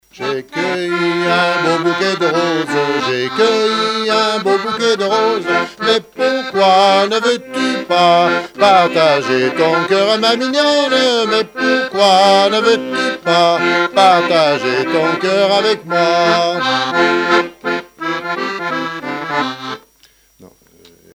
Chants brefs - A danser
danse : scottich sept pas
répertoire de chansons, de danses et fables de La Fontaine
Pièce musicale inédite